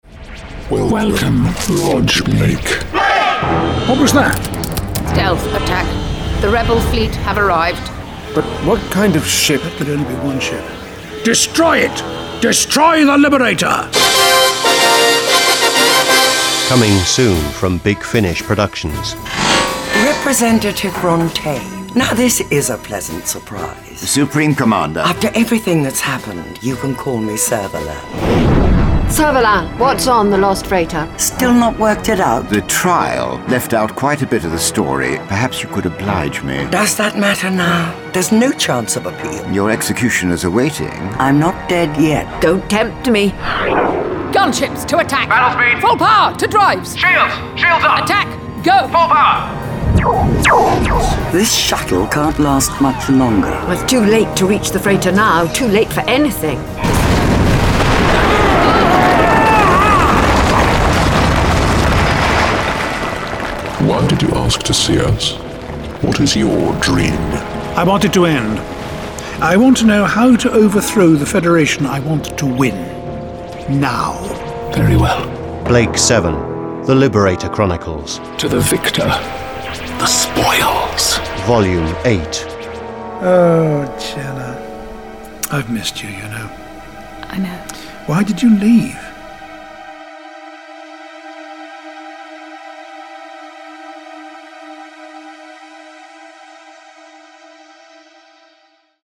Starring Gareth Thomas Jan Chappell